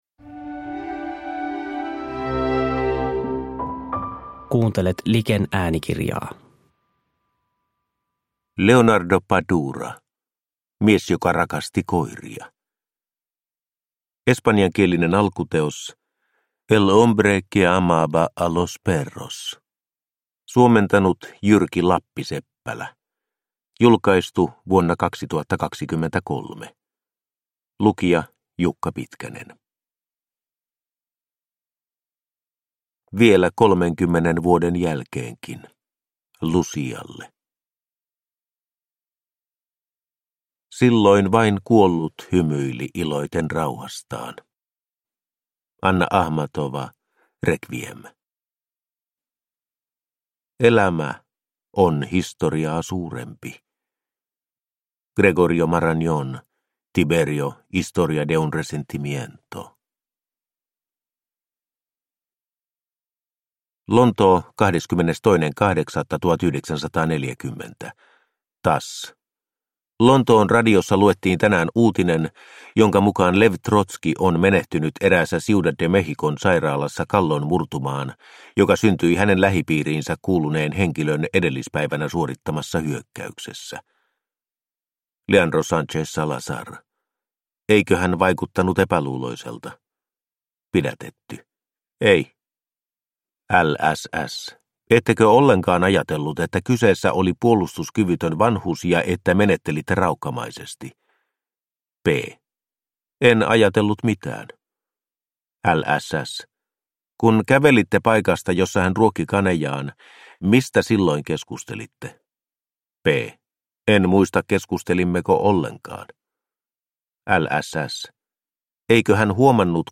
Mies joka rakasti koiria – Ljudbok – Laddas ner